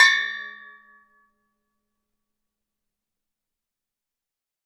锣 " 鼓、锣和风铃
描述：未混合的单轨录音具有16英寸直径鼓架的节奏节拍，由30英寸的Zildjian锣强调，以柔和的风铃结束。在MST的下午6点录制21215，使用板载麦克风的Zoom H4N，在前中心设置8英尺，距离地面2英尺。
标签： 放松 打击乐 环境 现场记录 编钟
声道立体声